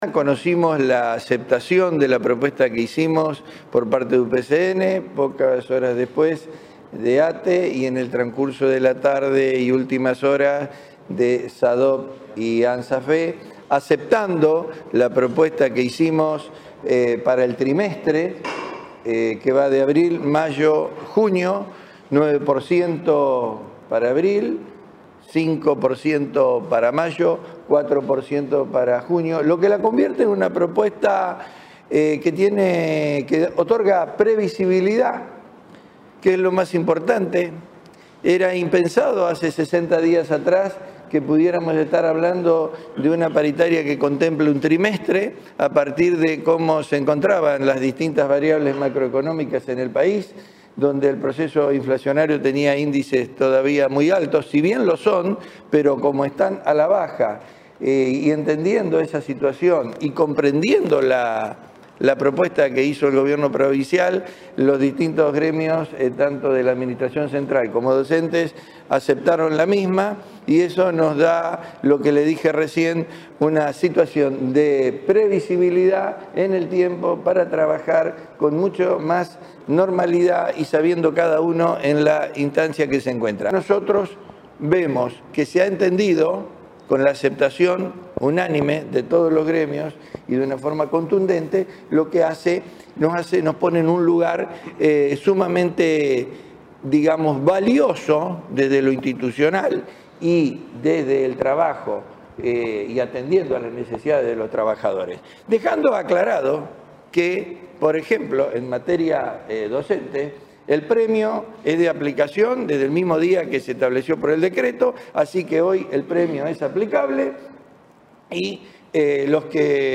Los ministros de Gobierno e Innovación Pública, Fabián Bastia; y de Educación, José Goity; brindaron una conferencia de prensa este jueves en Casa de Gobierno, donde destacaron la aceptación de la propuesta paritaria por parte de los trabajadores de los distintos eslabones del estado, consistente en 9 % para abril, 5 % para mayo y 4 % para junio, completando un 18 % para el trimestre, y que será tenido en cuenta para el medio aguinaldo.